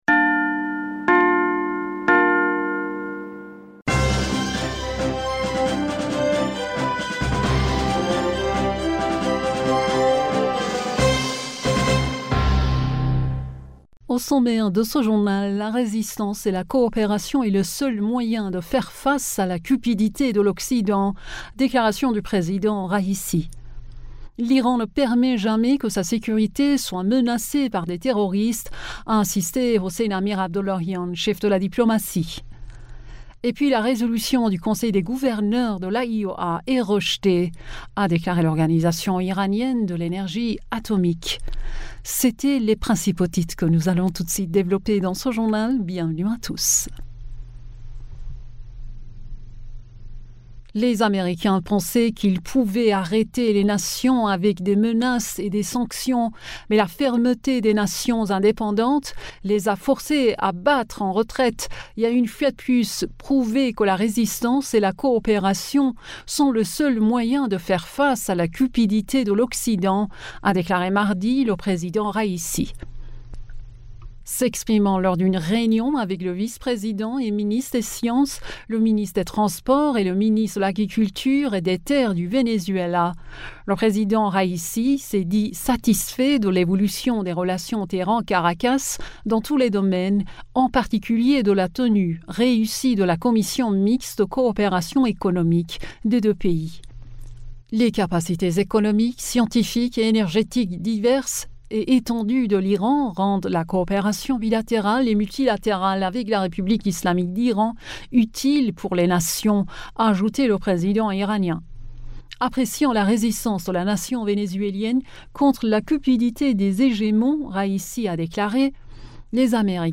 Bulletin d'information du 16 Novembre